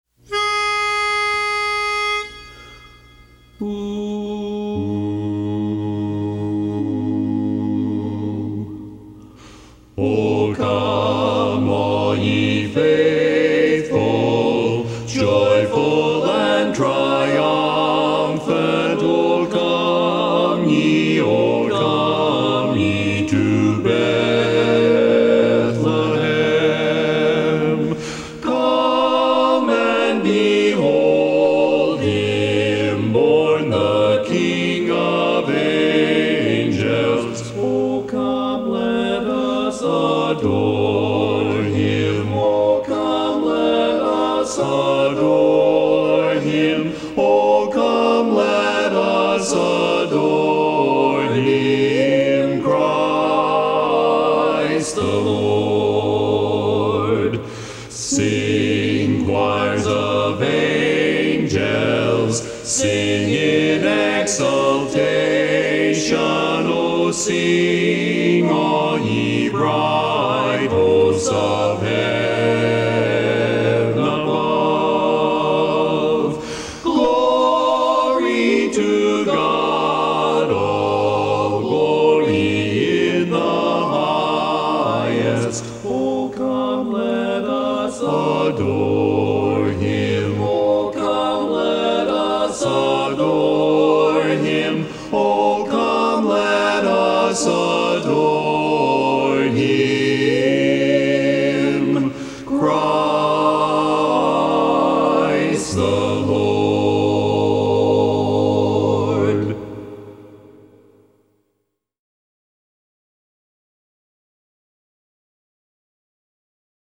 Christmas Songs
Barbershop
Bass